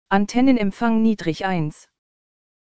Anbei fehlende Systemsounds.